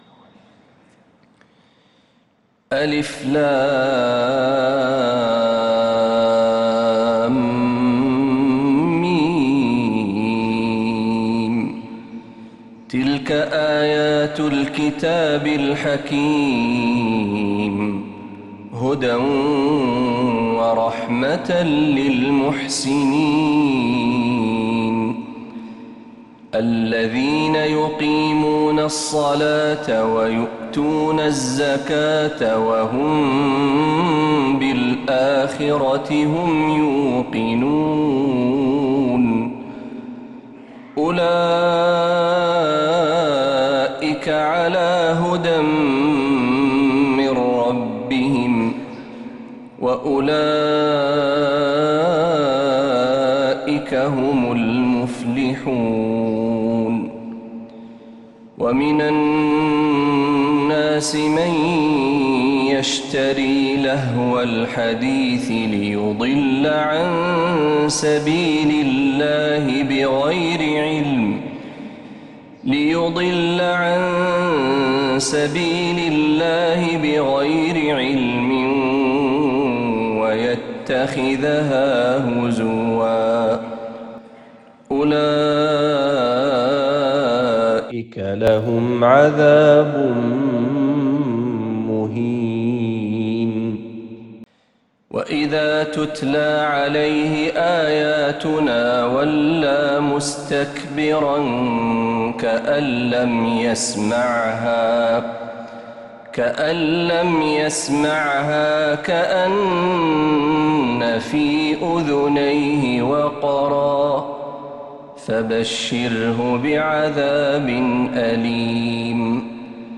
سورة لقمان كاملة من الحرم النبوي